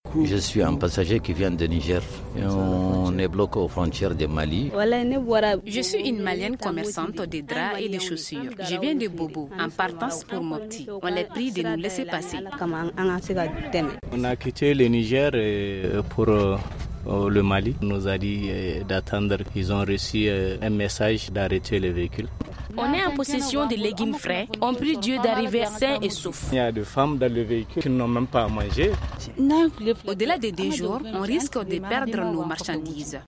Au corridor Mali-Burkina Faso, plusieurs véhicules de transports sont bloqués au poste des douanes de Koury à cause de la fermeture des frontières. Des voyageurs mécontents, dénoncent les sanctions prises par les Chefs D’États Ouest africains.